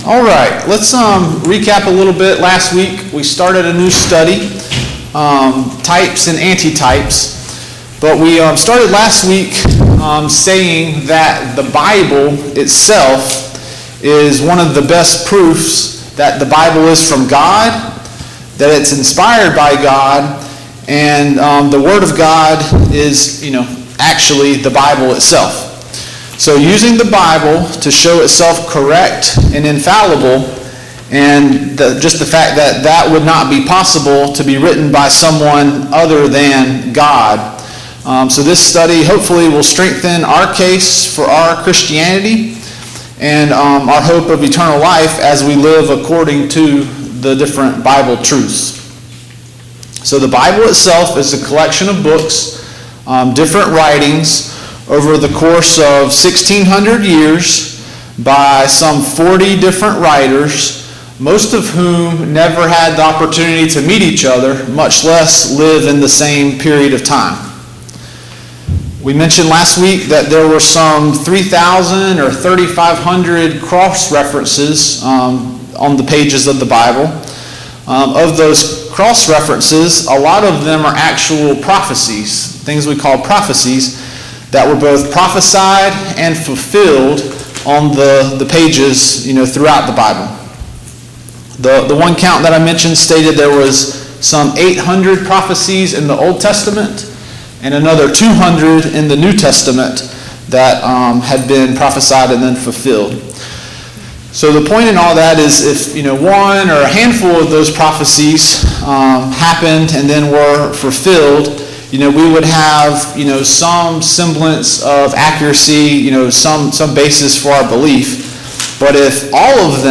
Study of Prophecy Service Type: Sunday Morning Bible Class « Paul’s third Missionary Journey